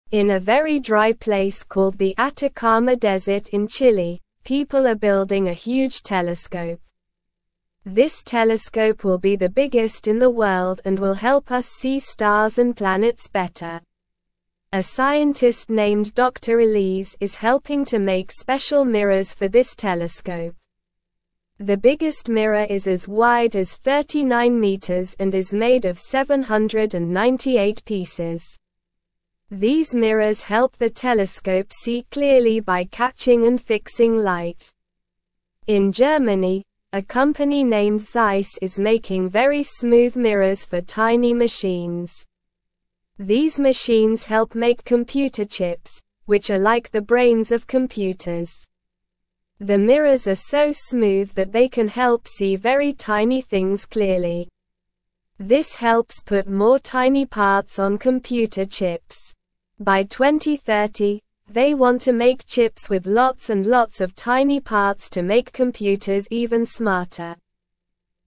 Slow
English-Newsroom-Beginner-SLOW-Reading-Building-a-Big-Telescope-and-Making-Smooth-Mirrors.mp3